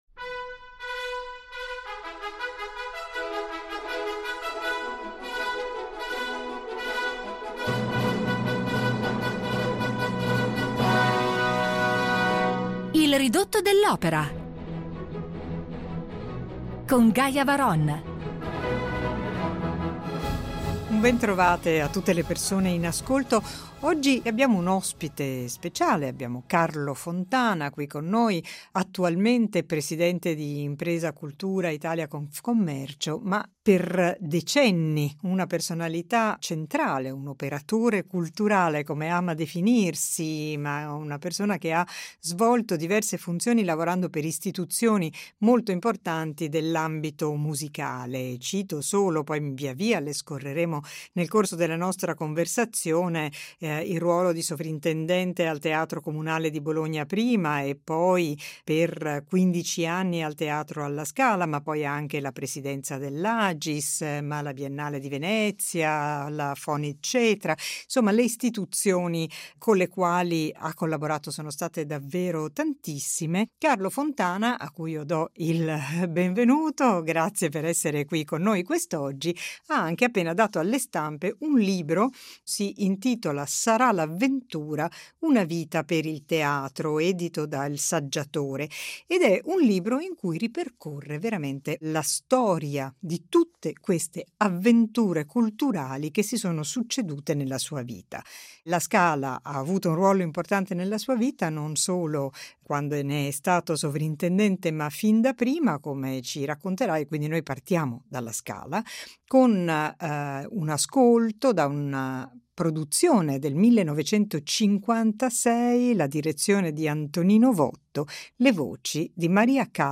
Ne parliamo con lui al Ridotto dell’opera, seguendo il filo del suo lavoro con cantanti e direttori, da Franco Corelli a Mariella Devia, da Luciano Pavarotti a Mirella Freni a Claudio Abbado, ripercorrendo le memorie di un operatore culturale che è stato ed è, innanzitutto, un grande appassionato d’opera.